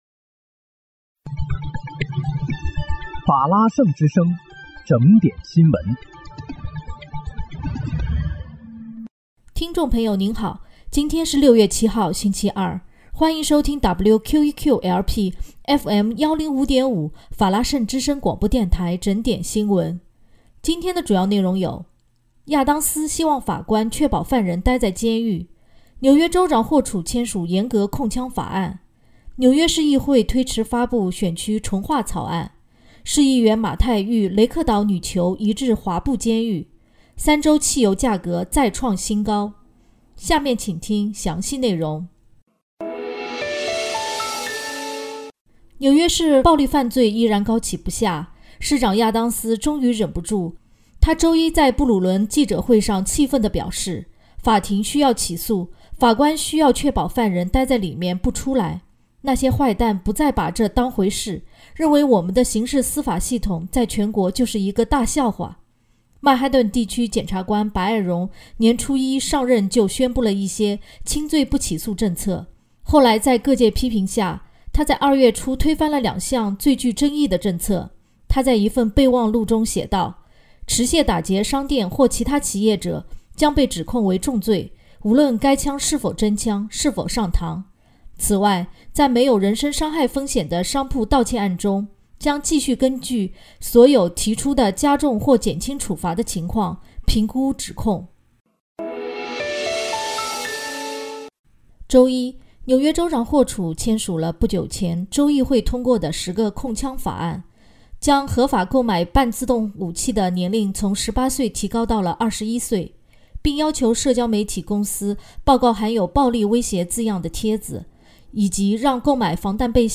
6月7日（星期二）纽约整点新闻